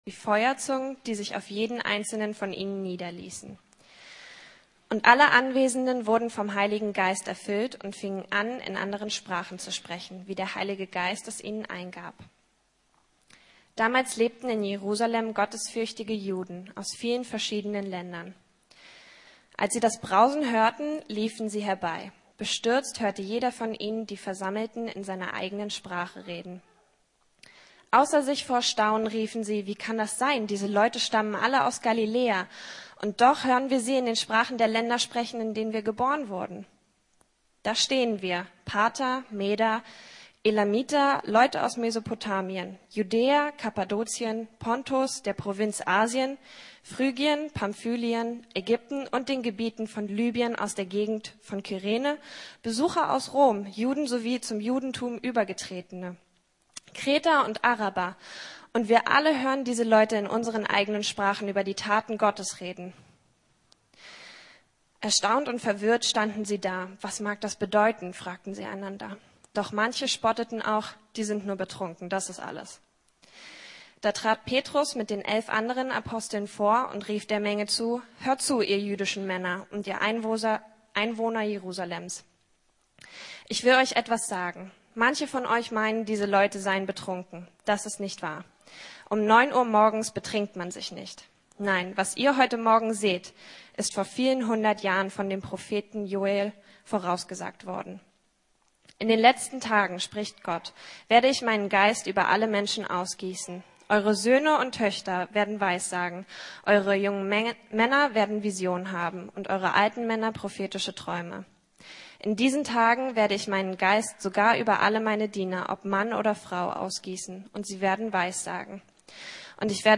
Vom Heiligen Geist erfüllt! ~ Predigten der LUKAS GEMEINDE Podcast